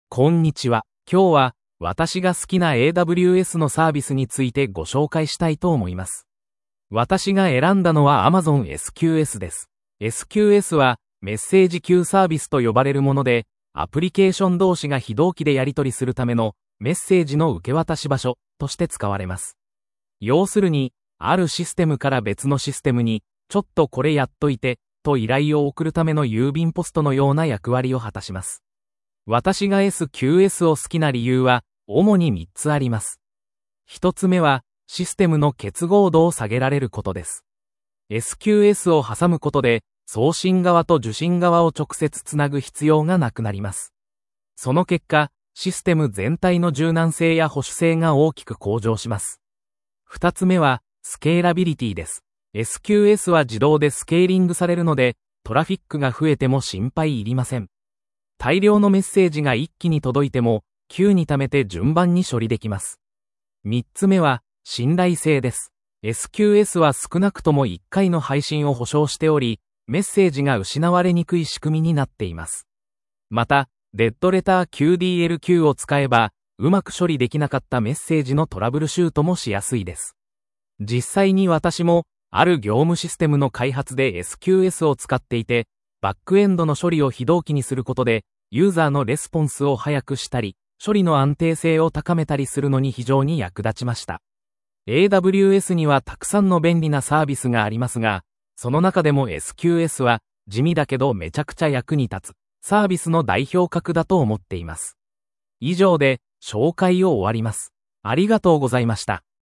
※今回は、音読さんという音声読み上げソフトでsample.mp3を作成しました。